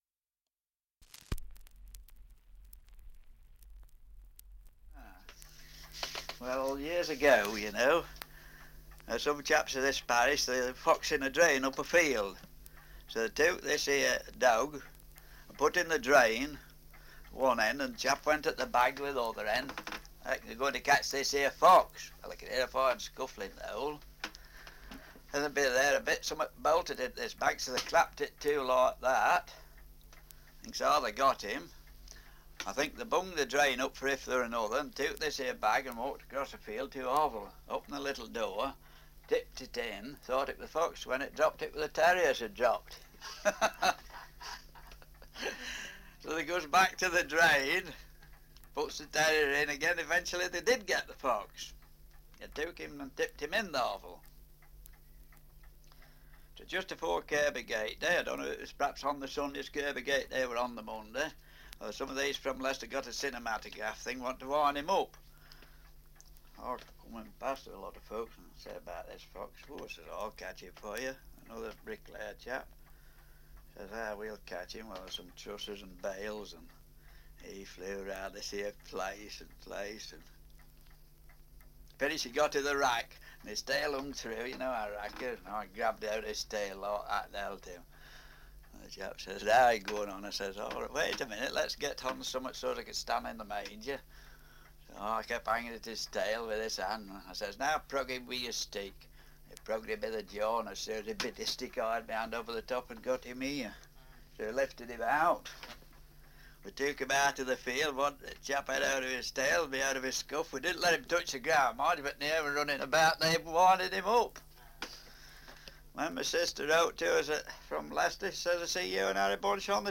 Survey of English Dialects recording in Harby, Leicestershire
78 r.p.m., cellulose nitrate on aluminium